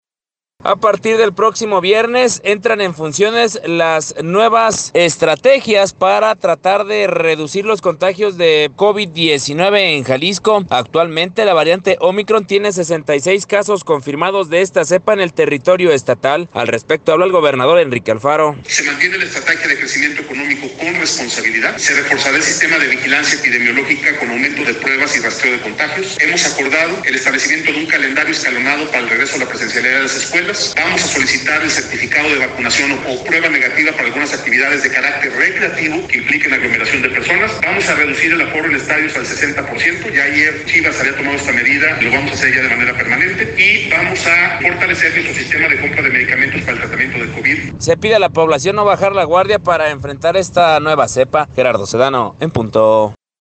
Al respecto habla el gobernador, Enrique Alfaro: